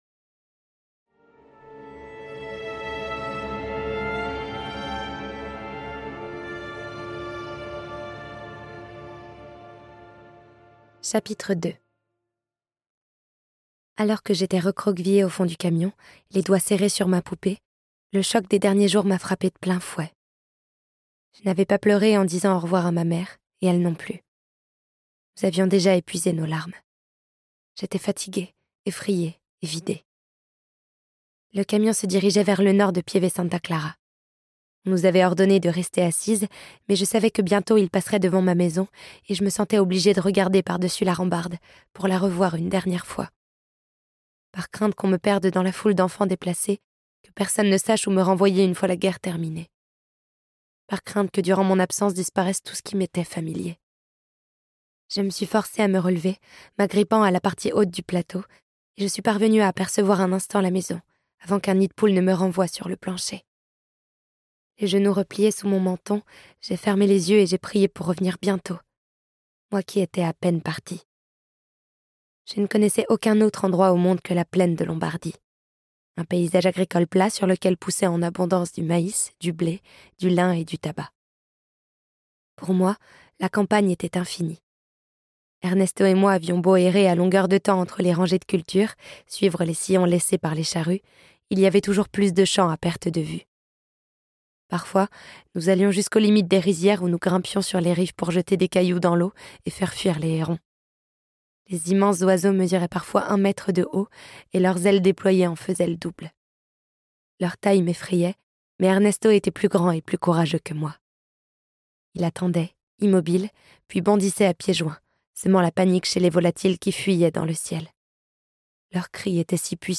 Le conflit est certes terminé, mais la vie n'est pas plus douce pour les Ponti...Ce livre audio est interprété par une voix humaine, dans le respect des engagements d'Hardigan.